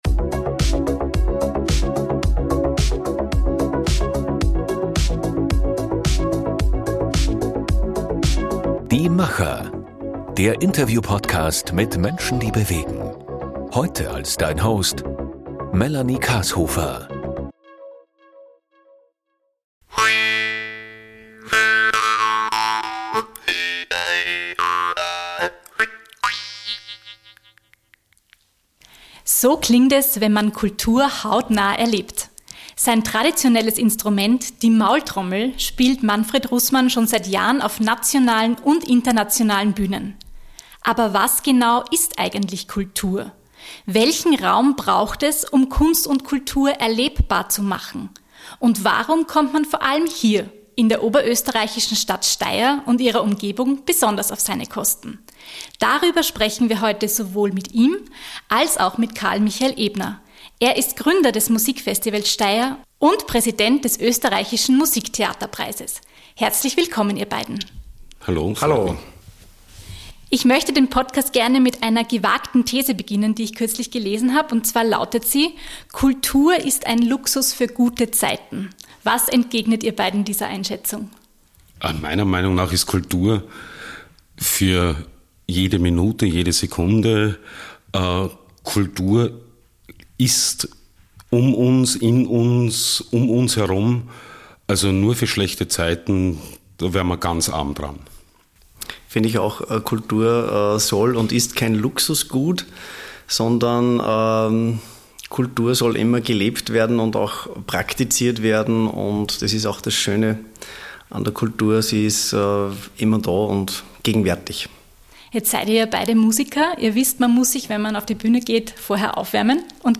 Podcastinterview